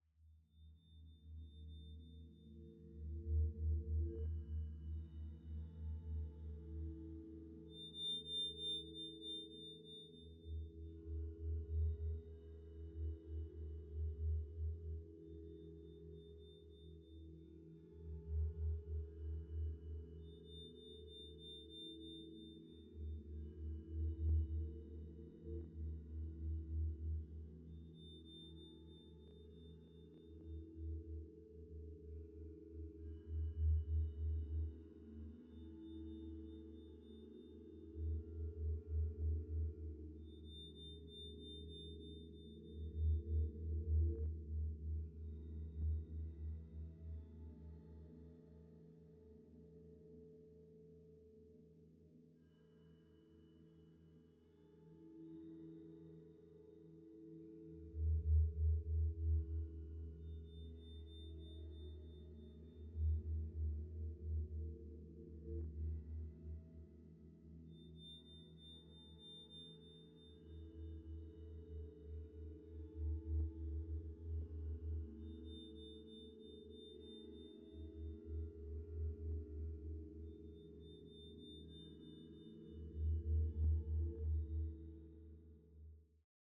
File:Fireplace(293BNew).ogg